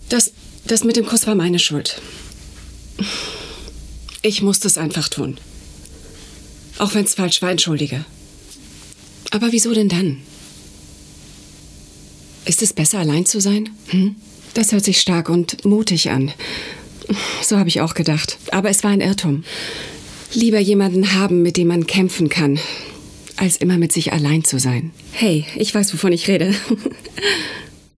Booking Sprecherin